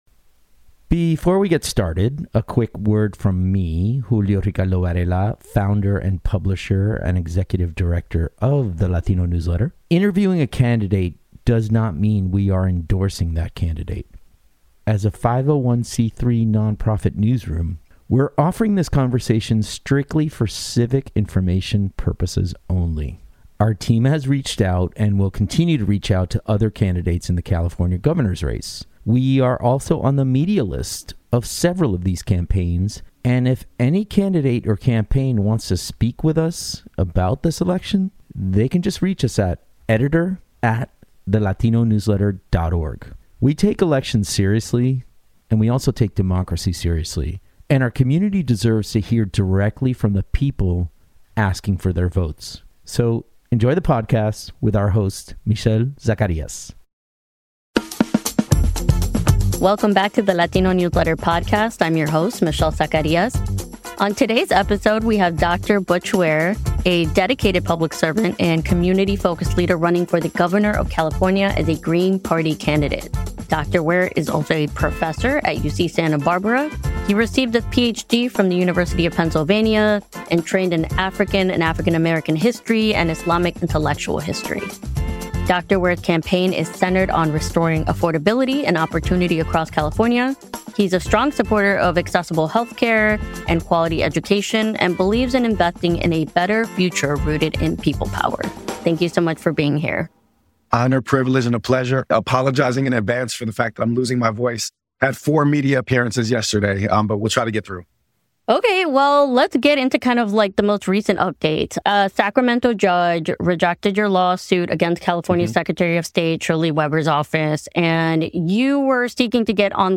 Here at The Latino Newsletter, interviewing a candidate does not mean we are endorsing that candidate.